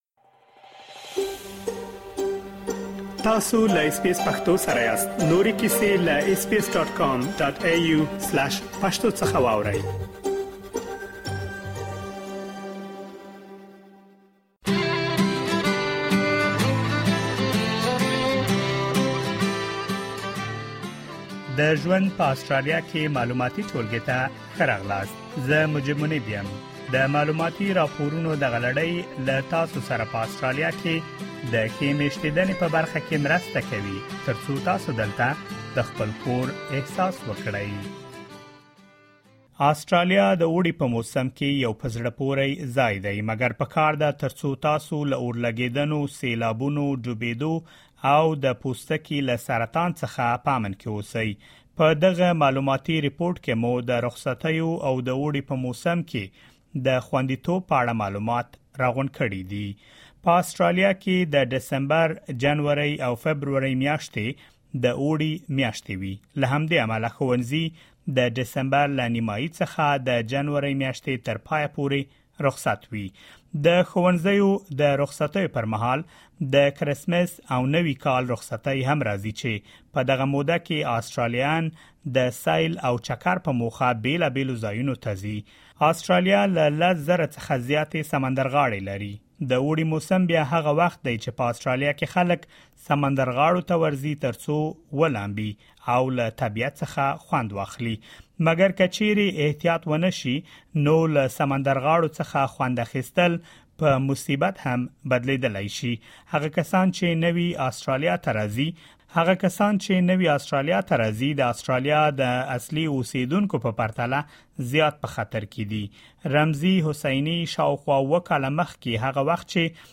Australia is an amazing place during warmer months, but you should be aware of hazards such as bushfires, floods, water dangers and heatwaves. In this informative report, we have gathered information about holidays and summer safety in Australia.